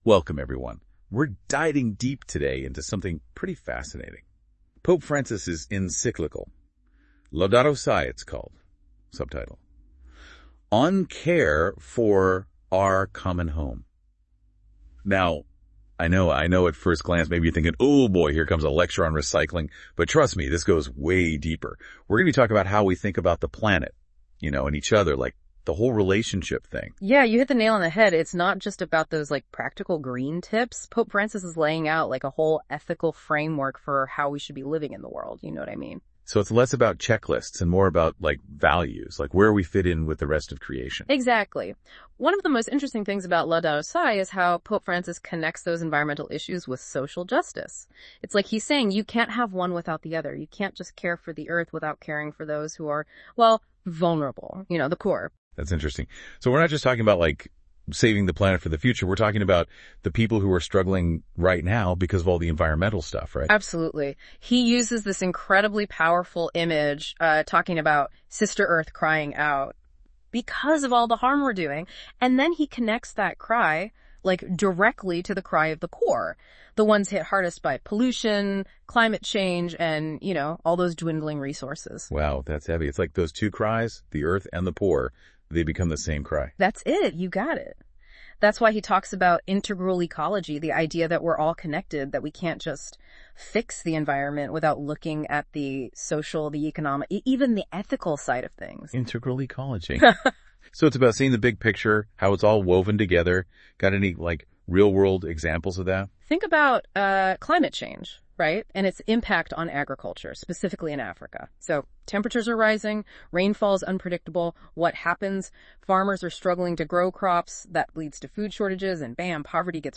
Listen to this AI-powered Laudato Si summary.